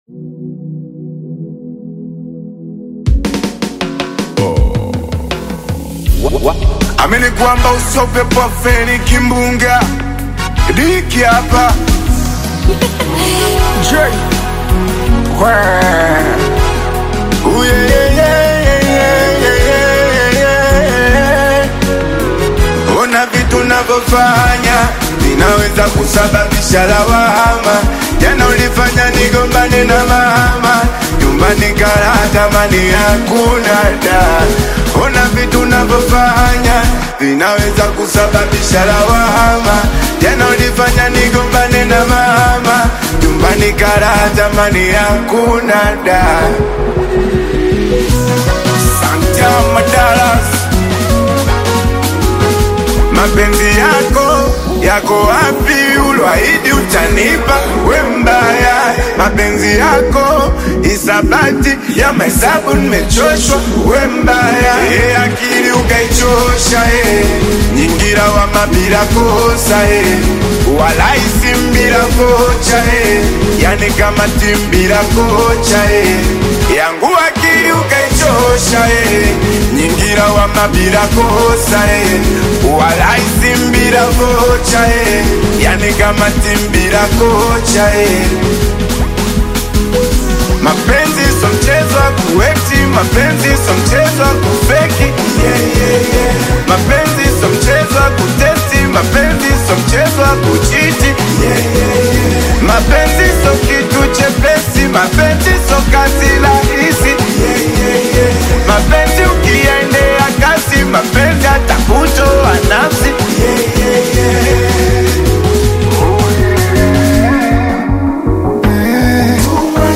Singeli/Afro-dance single